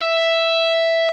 guitar_000.ogg